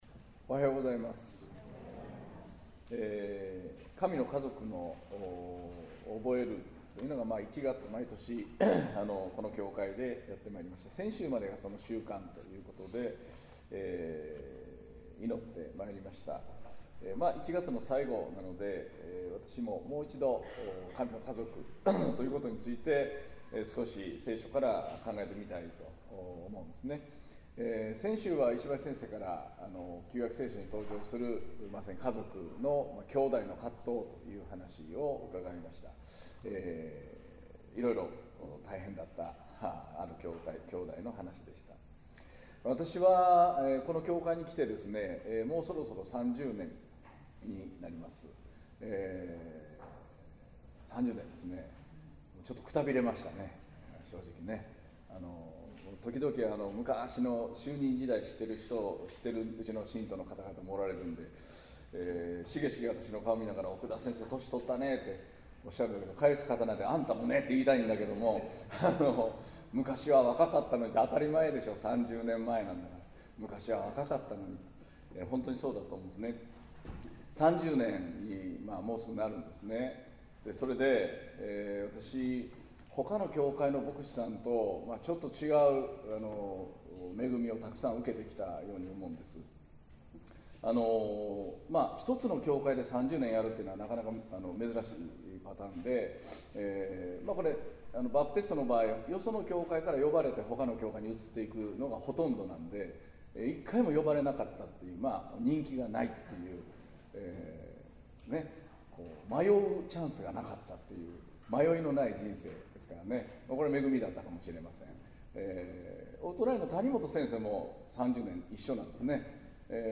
2019年1月27日（日）主日礼拝 宣教題「主よ、共に宿りませ」 | 東八幡キリスト教会
宣教